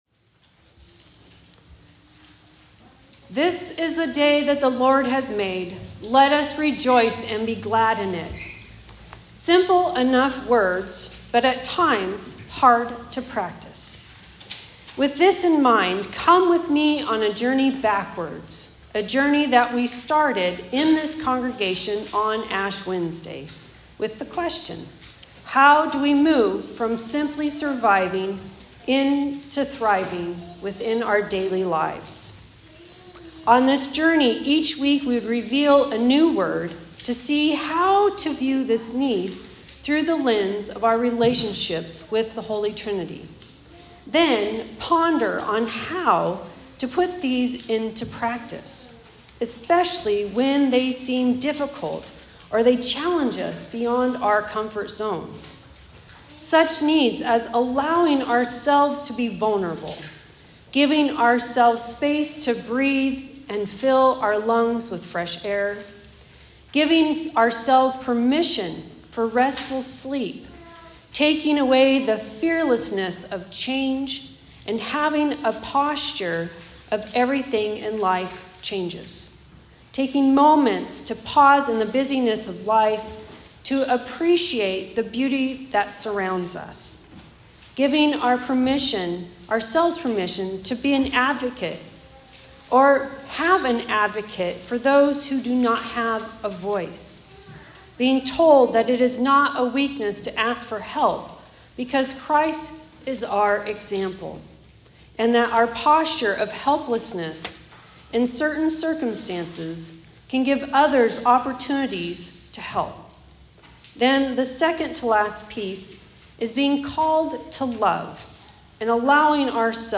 Sermons | Fir-Conway Lutheran Church